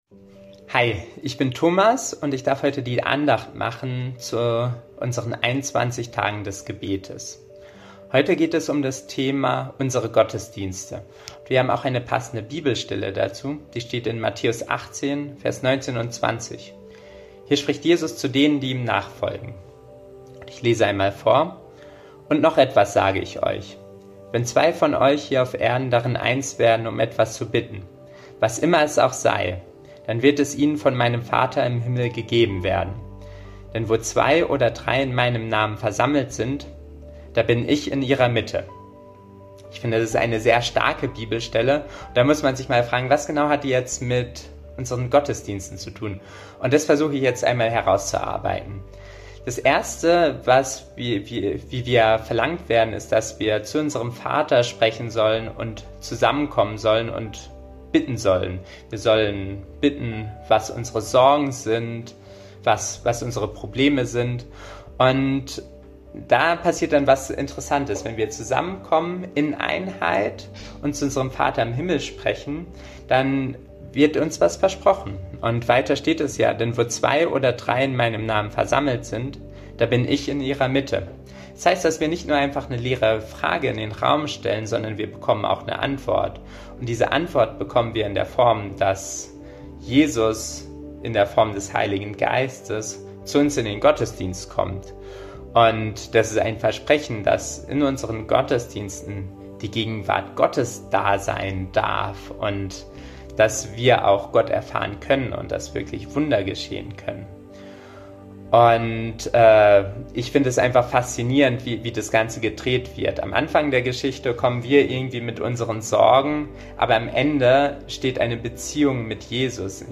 Tag 7 der Andacht zu unseren 21 Tagen Fasten & Gebet